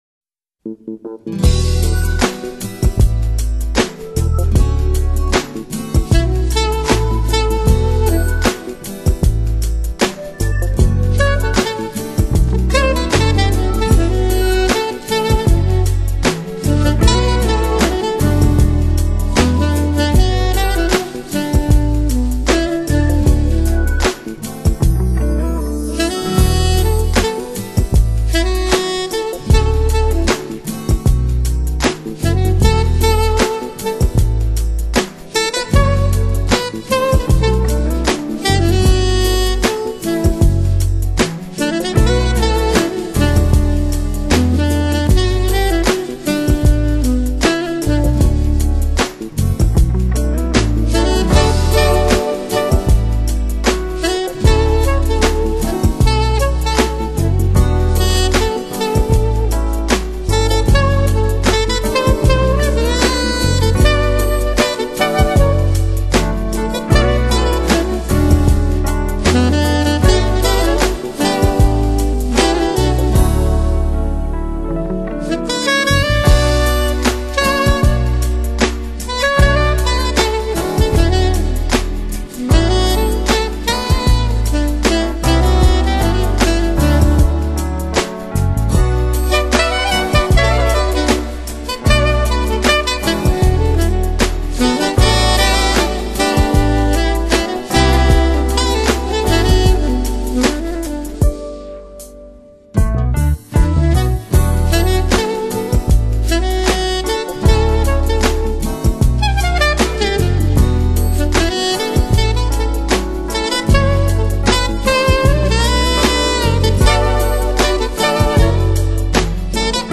专辑类型：Smooth Jazz